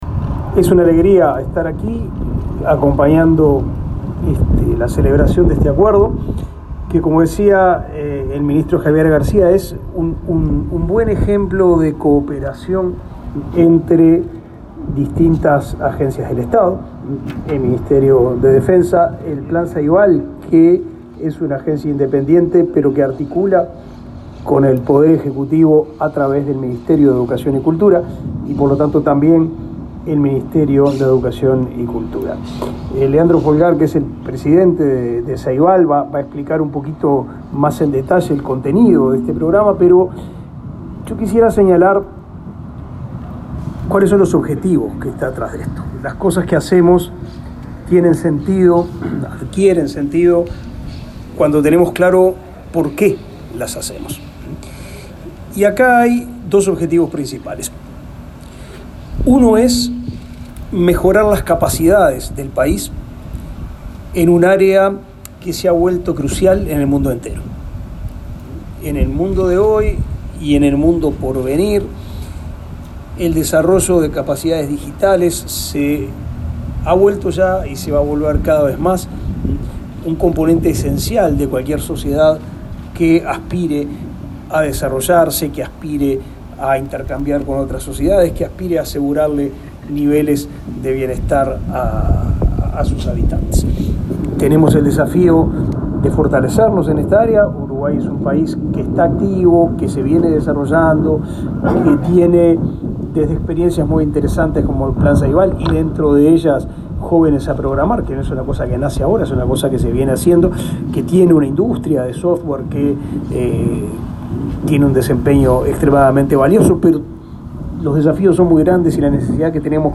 Declaraciones de Pablo da Silveira, ministro de Educación y Cultura